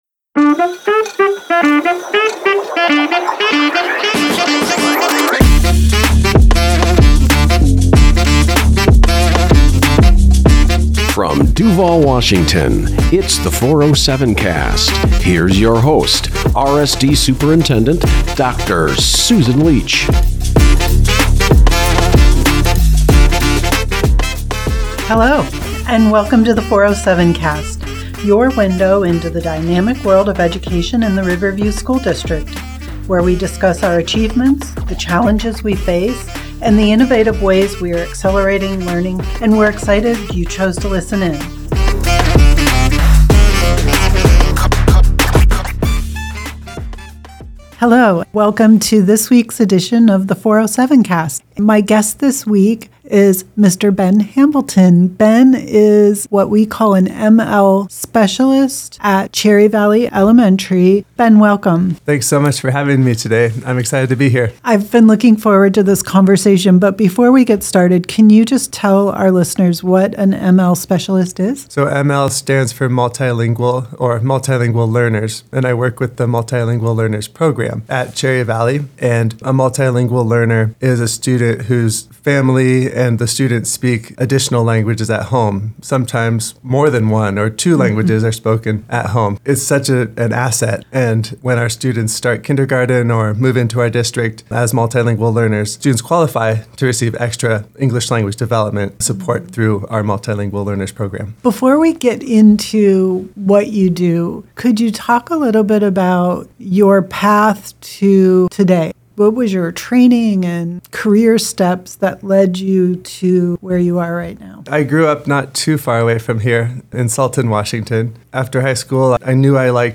Student and family support, strategy, technology and the benefits of becoming multilingual are all a part of the conversation.